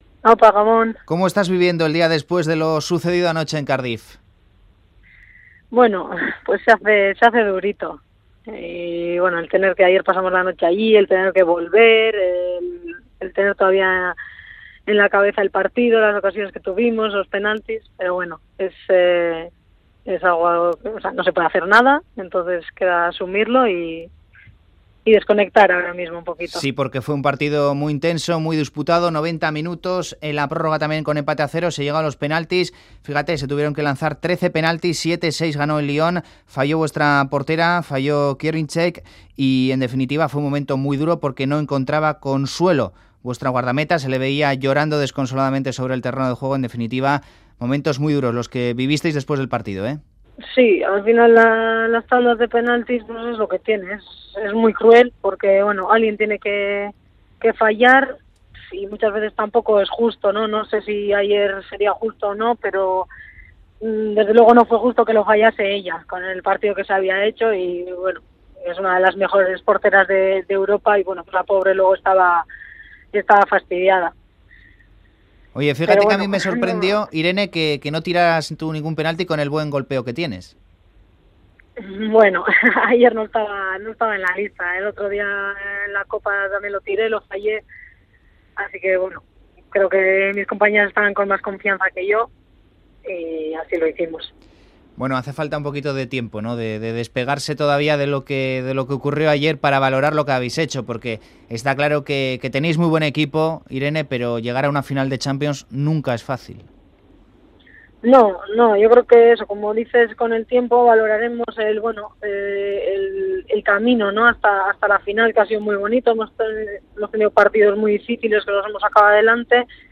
La futbolista guipuzcoana atiende la llamada de Fuera de Juego tras proclamarse subcampeona de Europa con el París Saint Germain.